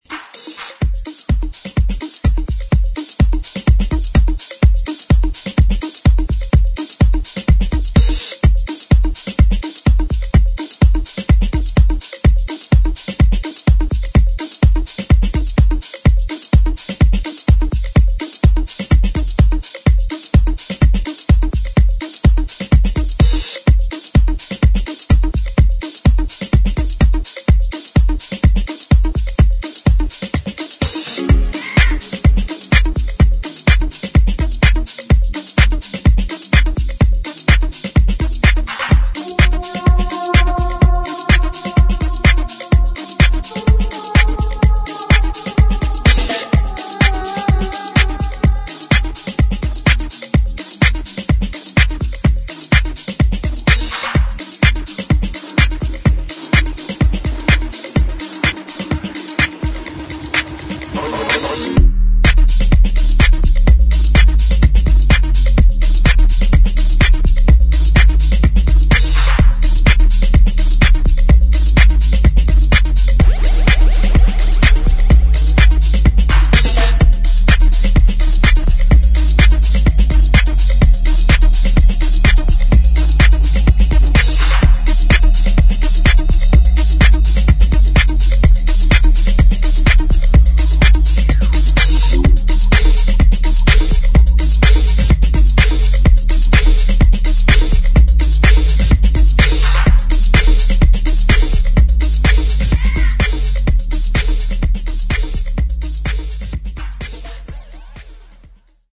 [ HOUSE | DISCO ]